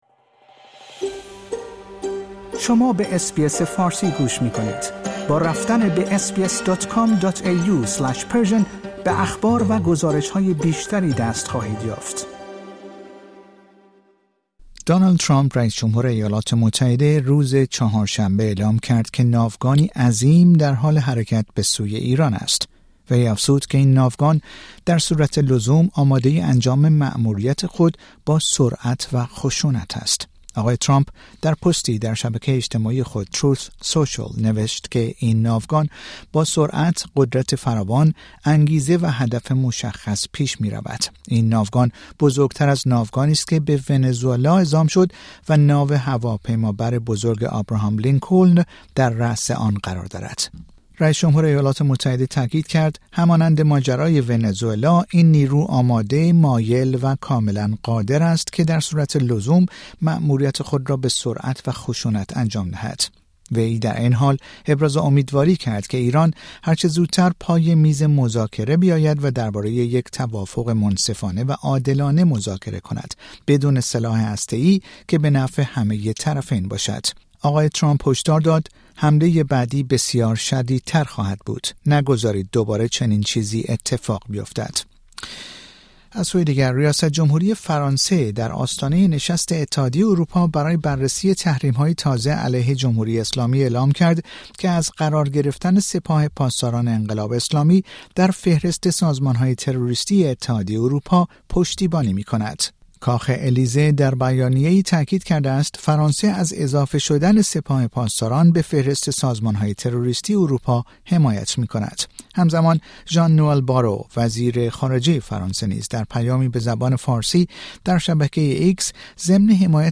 سرخط اخبار دانلد ترامپ رئیس‌ جمهور ایالات متحده روز چهارشنبه اعلام کرد که ناوگانی عظیم در حال حرکت به سوی ایران است.